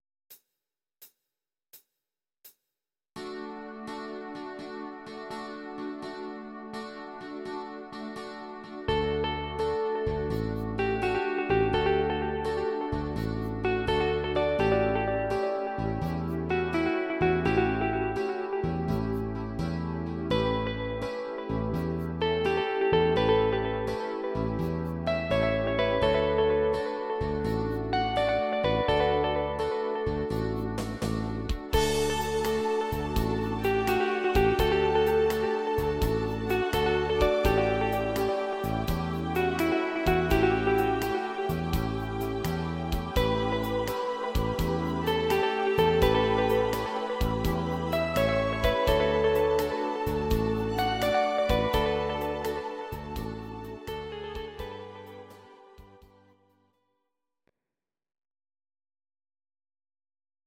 These are MP3 versions of our MIDI file catalogue.
Please note: no vocals and no karaoke included.
Gitarre